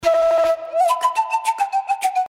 • Качество: 320, Stereo
Флейта
этнические
Возможно, так звучит перуанская флейта